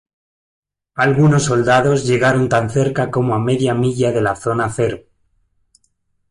Pronunciado como (IPA)
/ˈmiʝa/